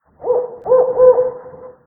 owl.ogg